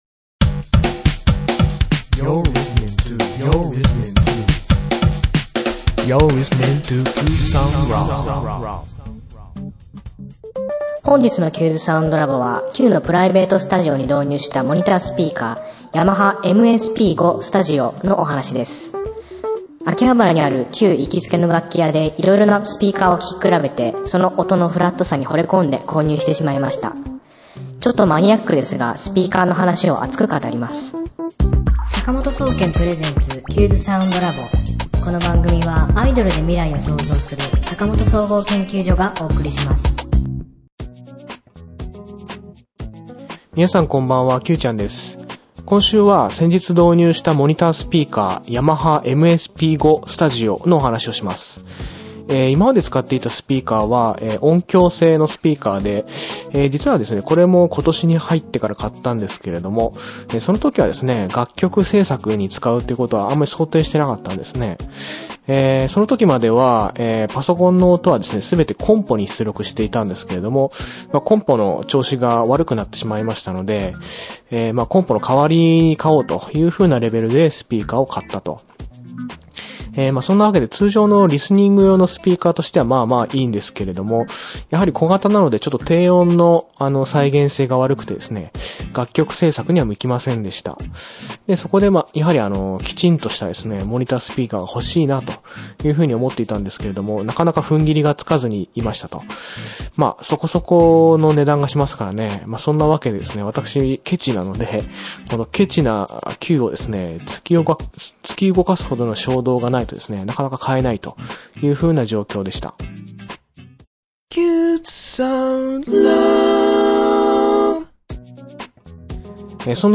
今週の挿入歌　『君とずっと暮らしたい』